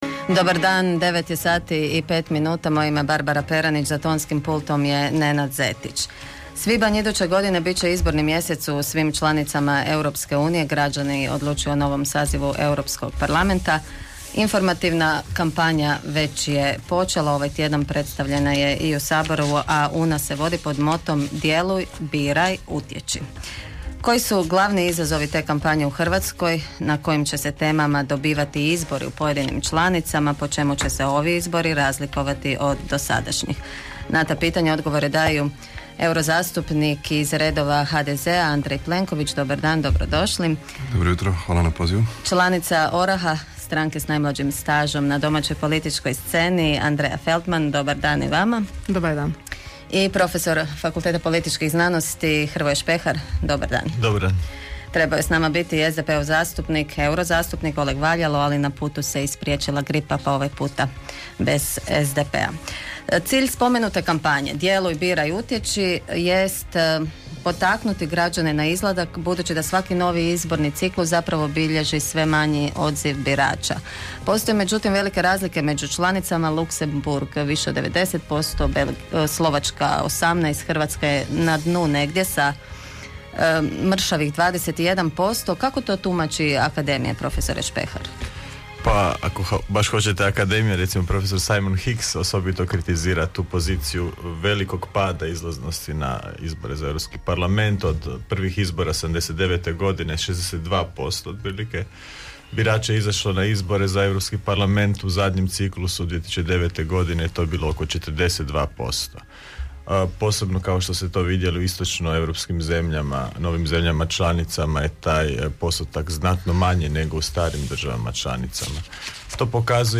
Poslušajte emisiju (MP3) Teme Europski parlament HDZ Intervjui Izbori za Europski parlament